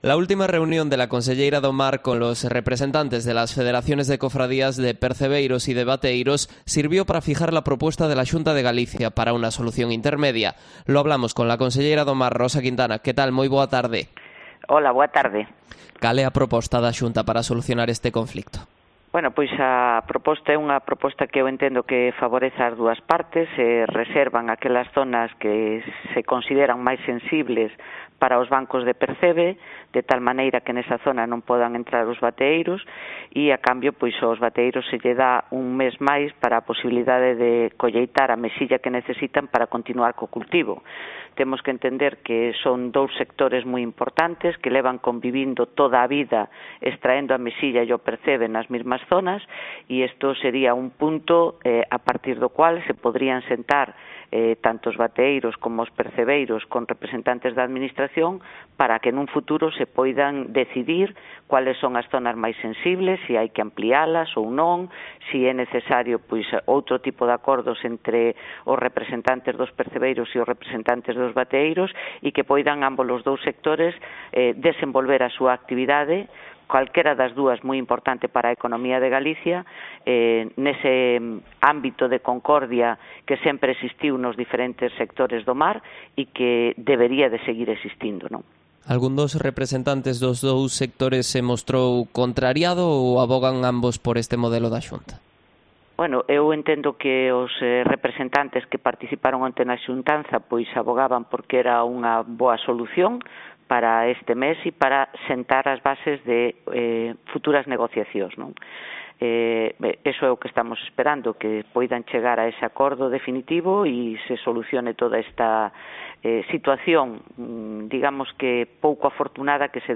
Entrevista a Rosa Quintana, conselleira do Mar